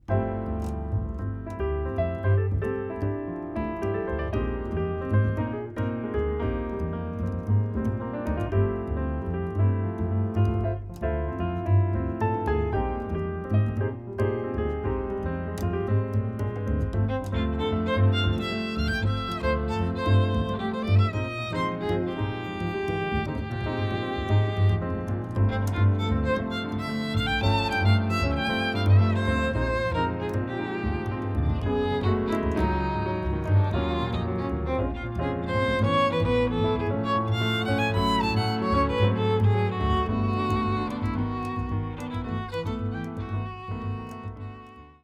Recorded on July.26th 2022 at Studio Happiness Engineer
vln